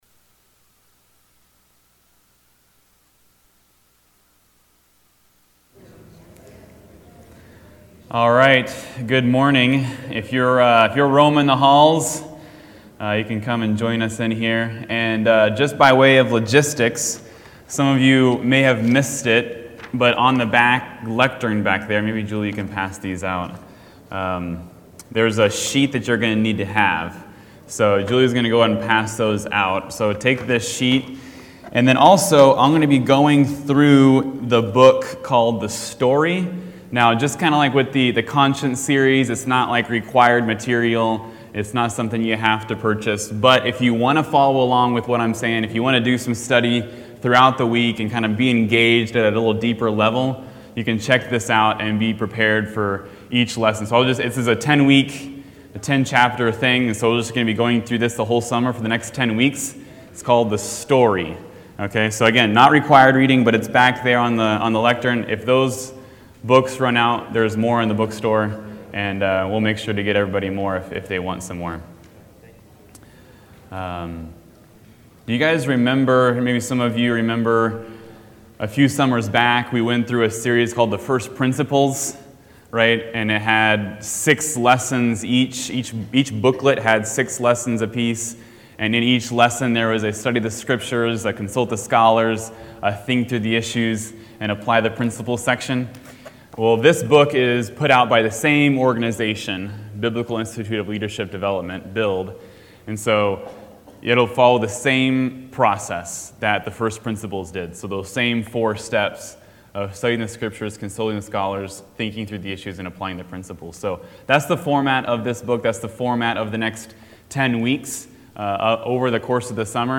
2021 The Story Genesis Transcript In this Adult Sunday School class